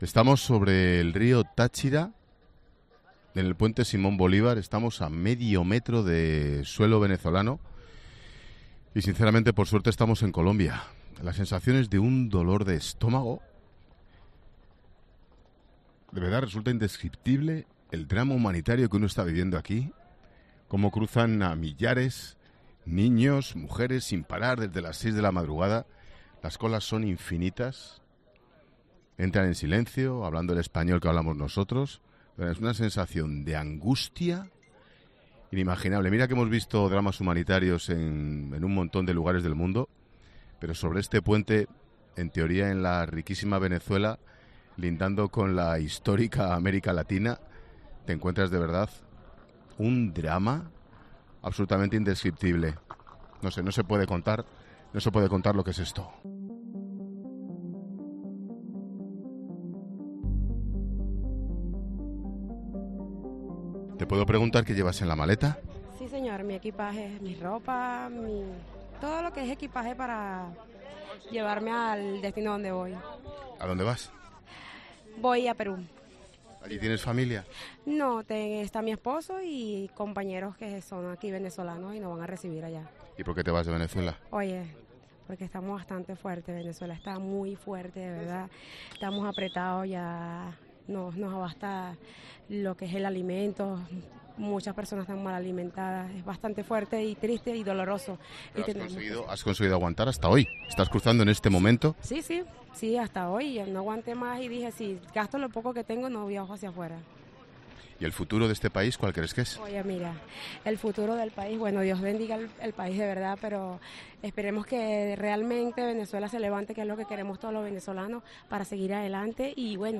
En la frontera entre Venezuela y Colombia, los gritos de un niño se mezclan con ritmos de salsa y merengue.